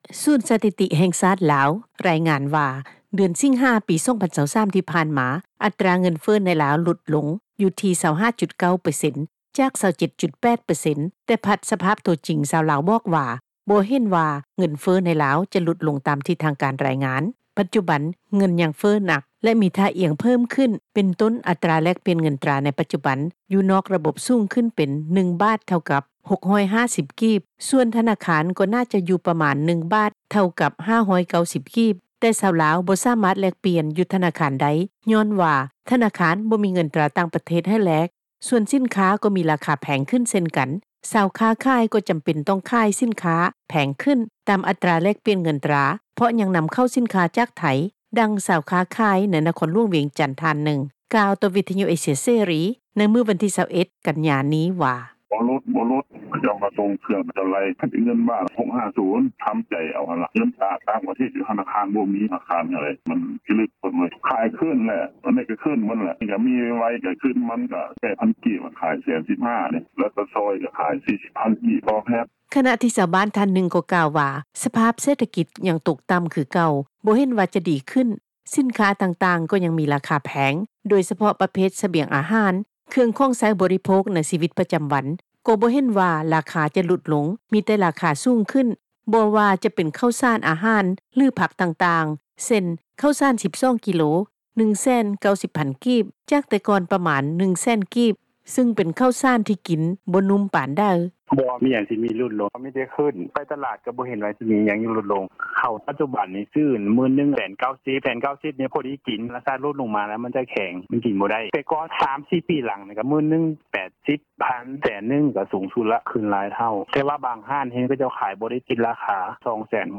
ນັກຂ່າວ ພົລເມືອງ
ດັ່ງ ຊາວຄ້າຂາຍ ໃນນະຄອນຫລວງວຽງຈັນ ທ່ານນຶ່ງ ກ່າວຕໍ່ວິທຍຸ ເອເຊັຽເສຣີ ໃນວັນທີ 21 ກັນຍາ ນີ້ວ່າ: